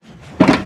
Minecraft Version Minecraft Version 1.21.5 Latest Release | Latest Snapshot 1.21.5 / assets / minecraft / sounds / block / shulker_box / close.ogg Compare With Compare With Latest Release | Latest Snapshot